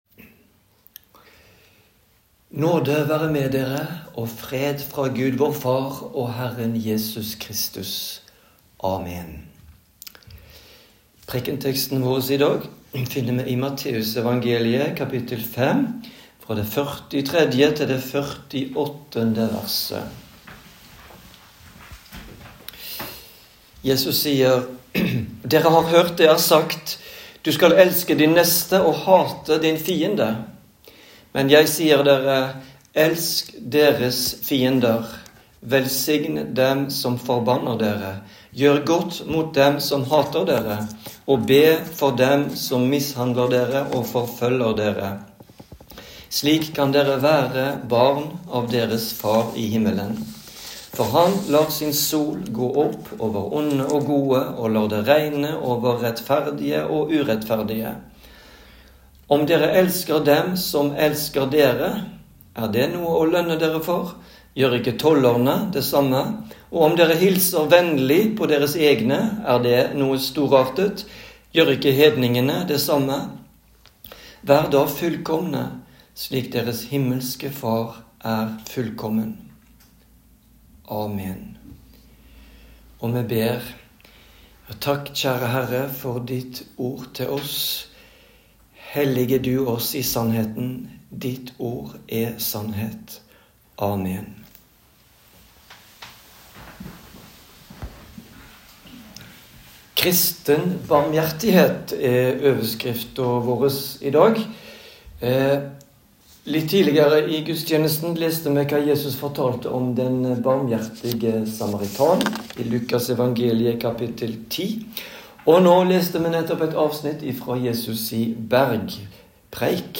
Preken
Preken-13-son-e-Tr.m4a